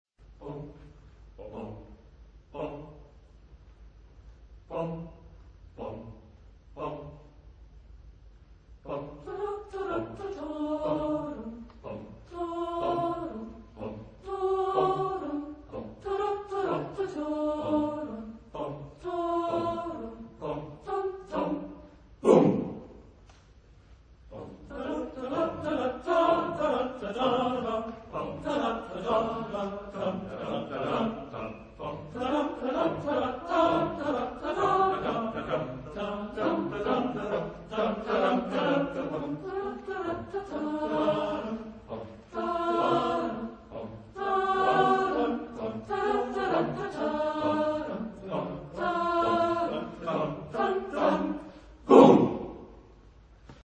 Texte en : sans paroles
Genre-Style-Forme : Marche
Type de choeur : SSAATTBB  (8 voix mixtes )
Tonalité : ré majeur